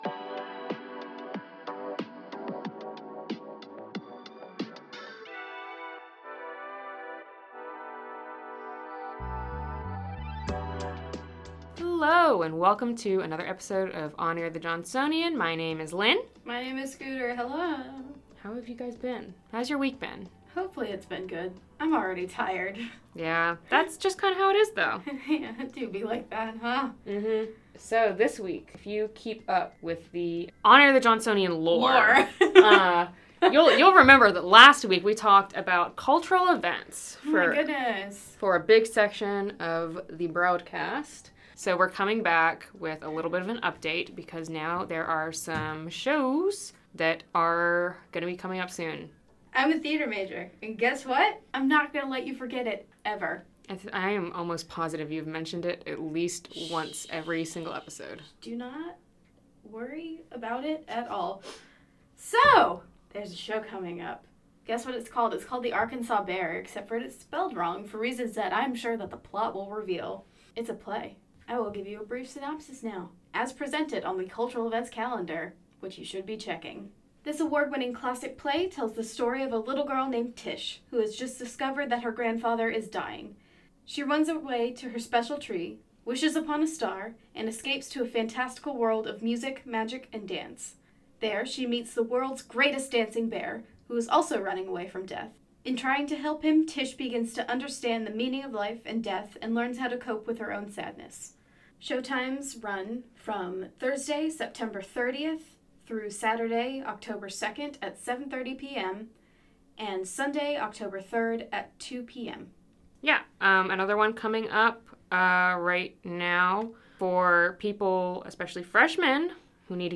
This week, your hosts received TWO WHOLE submissions, leading to discussions on peanut butter and roommate defense. Also, an interview regarding Wi-Fi and campus infrastructure.